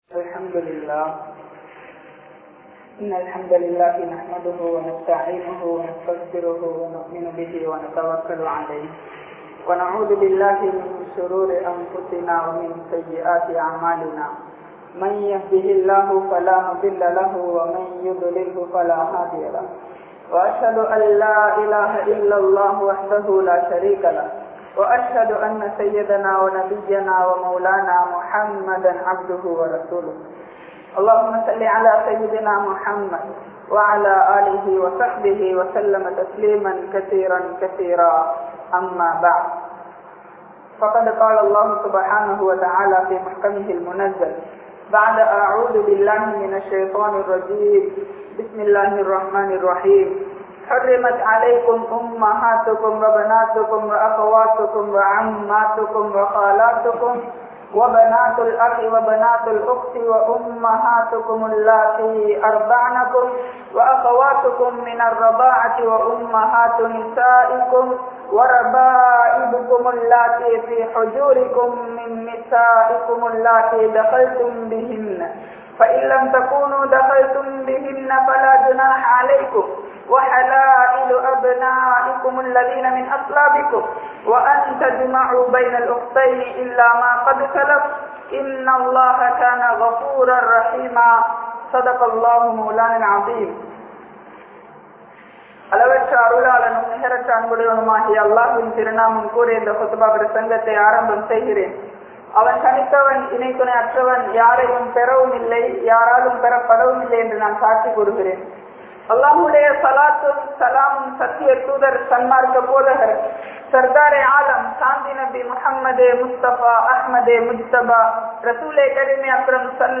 Mahram Peanuvathan Avasiyam (மஹ்ரம் பேணுவதன் அவசியம்) | Audio Bayans | All Ceylon Muslim Youth Community | Addalaichenai
Majmaulkareeb Jumuah Masjith